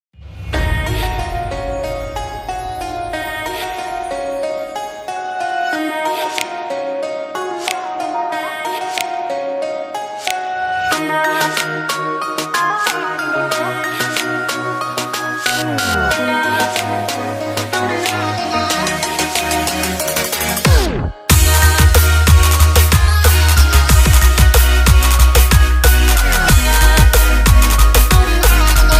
trap track
electronic duo